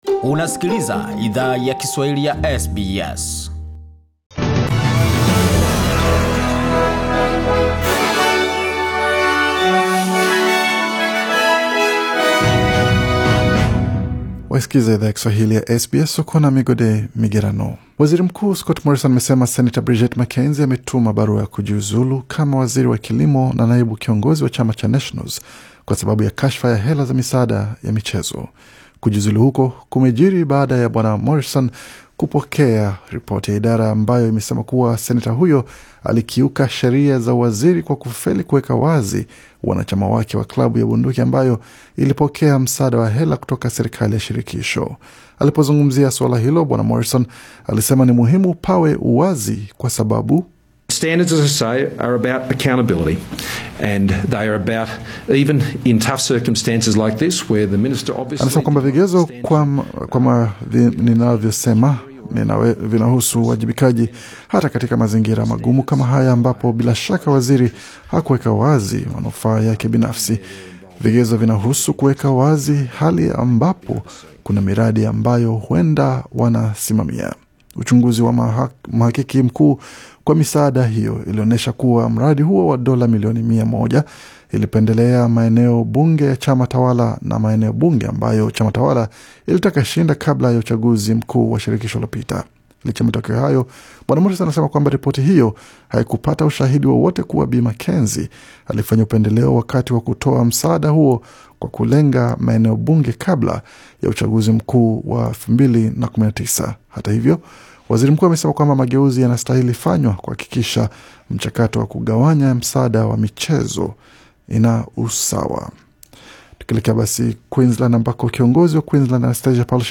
Taarifa za habari: Seneta Bridget McKenzie ajiuzulu